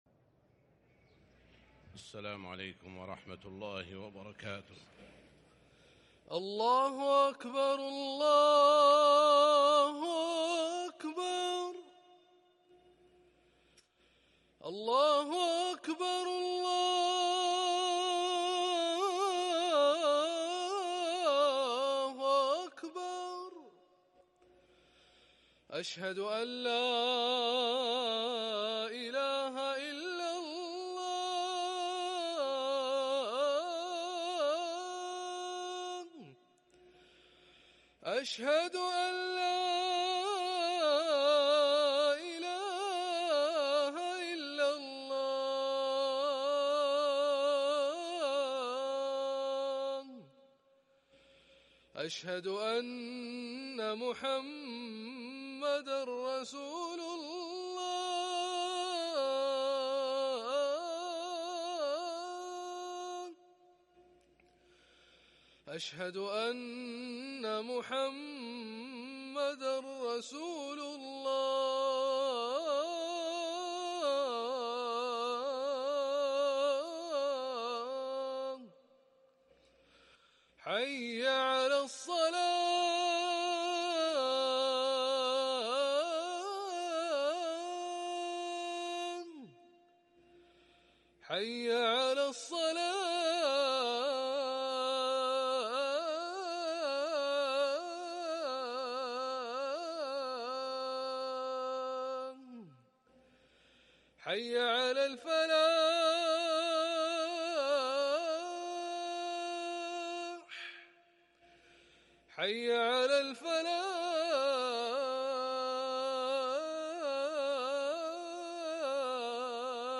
اذان الجمعة الثاني